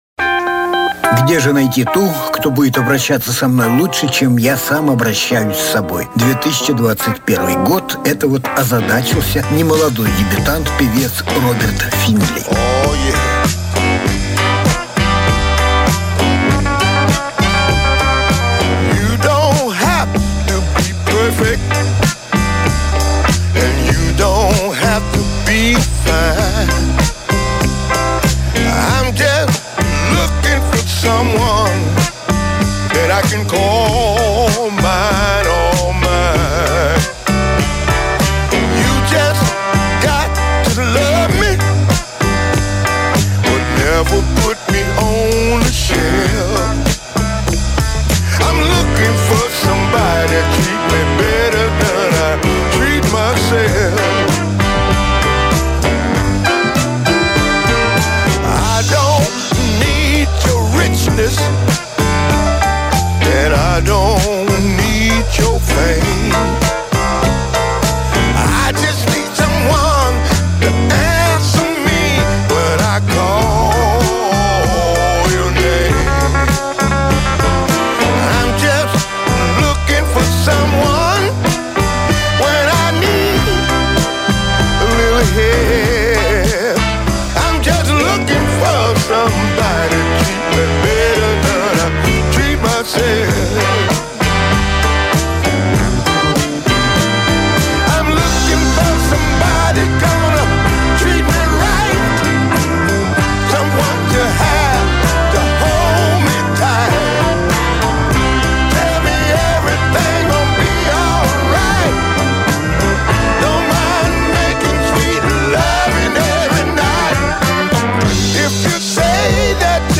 Lonely Nights" Жанр: Блюзы и блюзики СОДЕРЖАНИЕ 31.05.2021 Обзор блюзовых новинок.